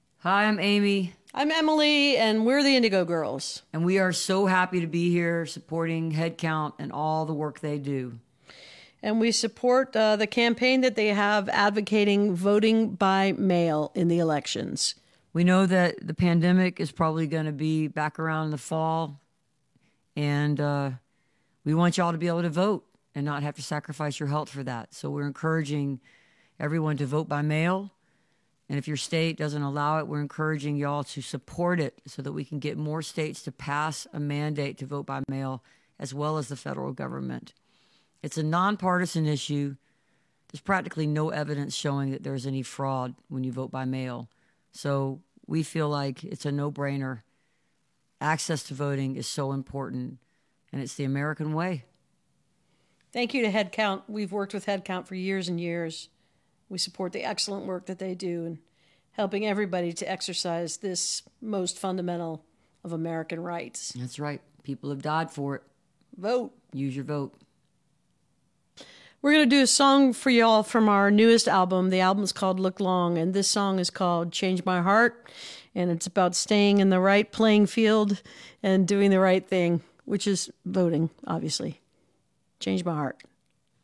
(captured from the facebook recording of the zoom broadcast)
02. talking with the crowd (1:23)